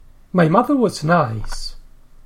sound_loud_speaker My mother was nice. ǀ m ˈmʌðə wəz ˈnaɪs ǀ